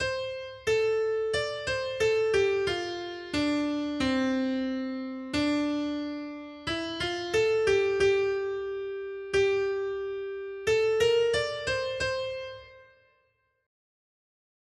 Noty Štítky, zpěvníky ol315.pdf responsoriální žalm Žaltář (Olejník) 315 Skrýt akordy R: Stvoř mi čisté srdce, Bože! 1.